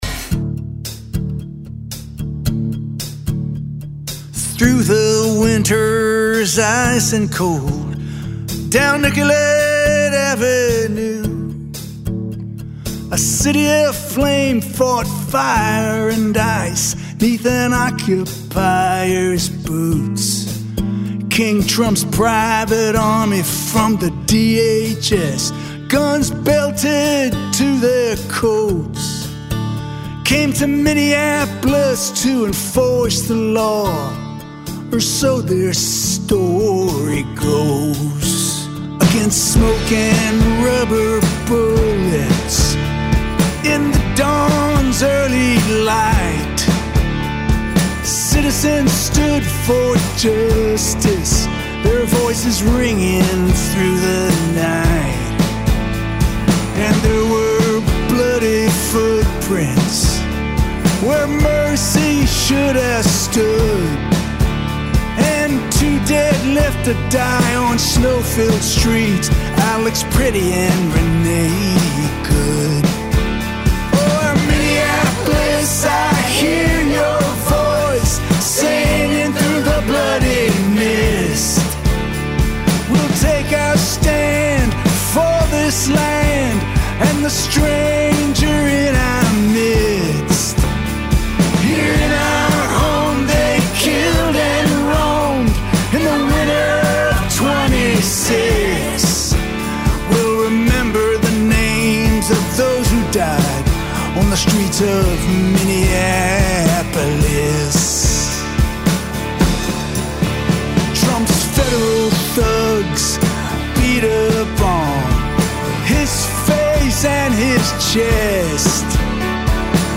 Un’ora di divagazione musicale